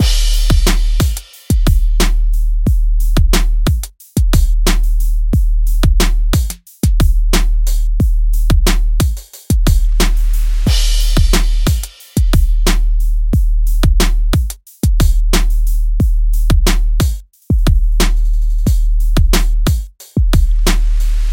旧学校 鼓声循环9
Tag: 90 bpm Hip Hop Loops Drum Loops 3.59 MB wav Key : Unknown